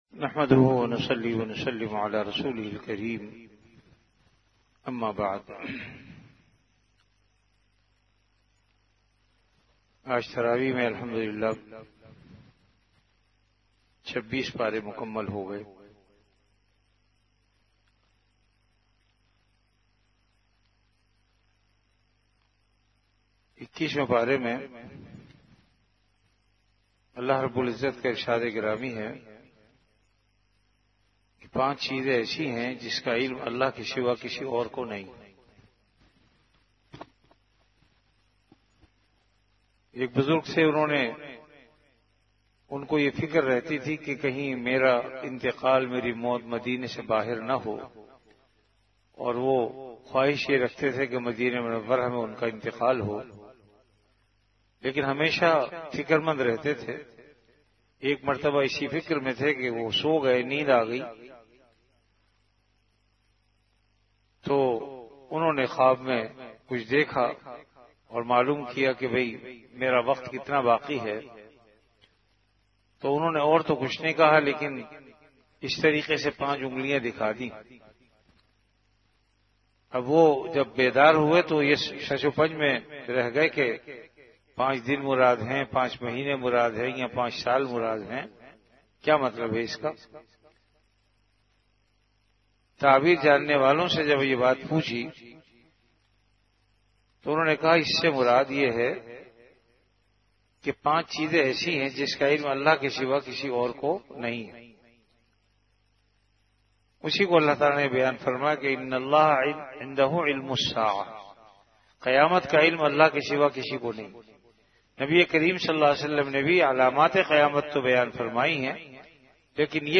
An Urdu Islamic audio lecture on Ramadan - Taraweeh Bayan, delivered at Jamia Masjid Bait-ul-Mukkaram, Karachi.